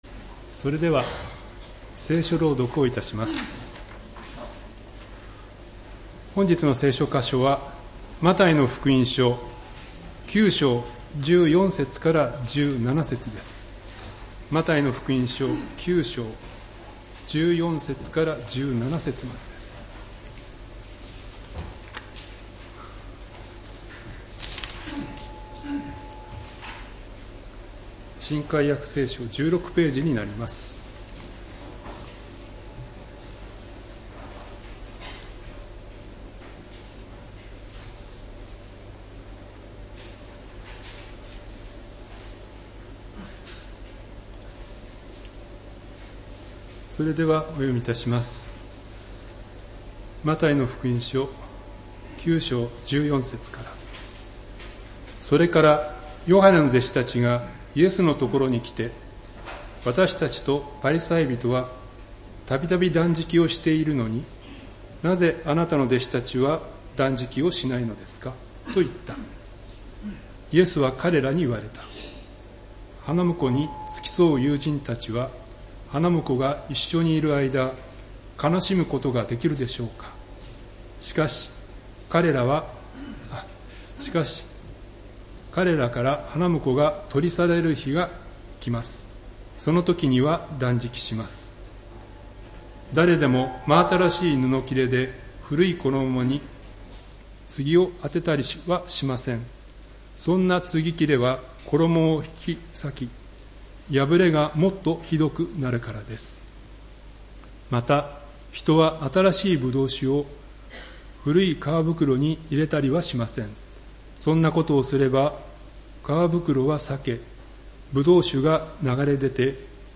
礼拝メッセージ「罪人を招くために」（11月30日）